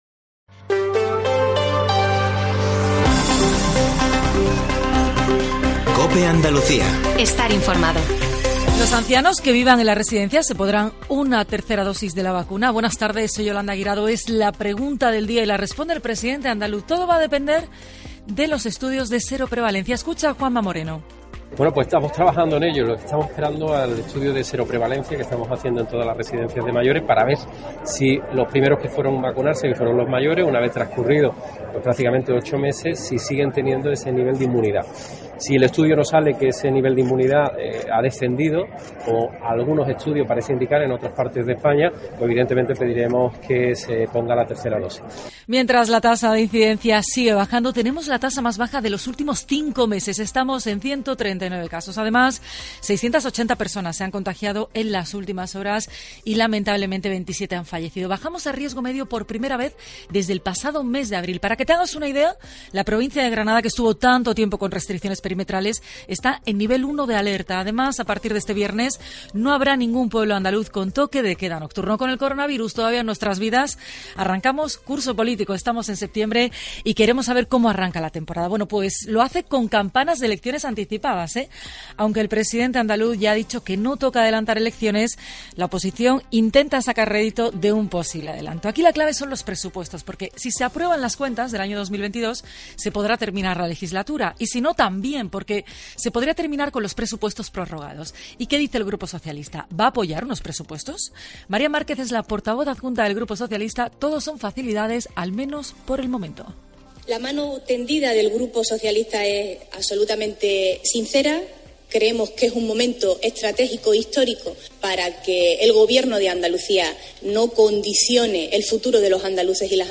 Elías Bendodo, portavoz de la Junta de Andalucía y consejero de la presidencia ha pasado este miércoles por los micrófonos de ‘La Linterna de COPE Andalucía’ para defender la postura de su partido las reacciones de la oposición en el Parlamento Andaluz.